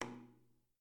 click-ambient-mute
ambient bath bubble burp click drain dribble drip sound effect free sound royalty free Nature